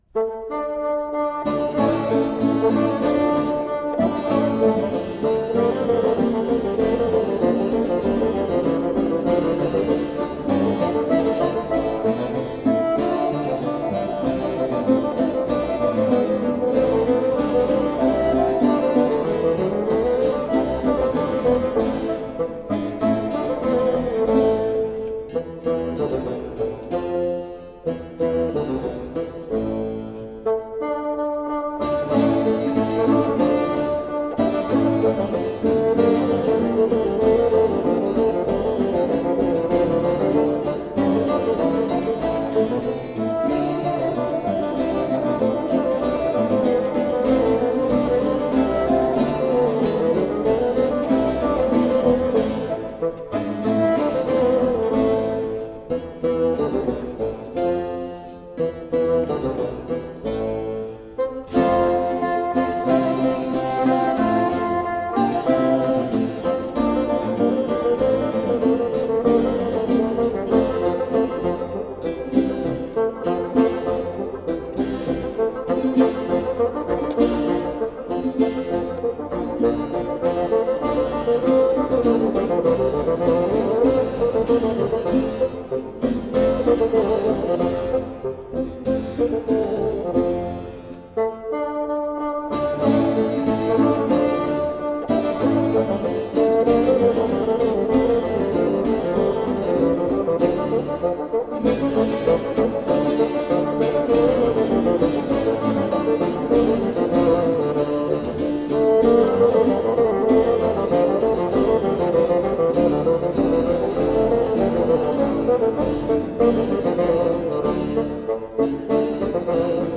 Buoyant baroque bassoon.
bright, warm tones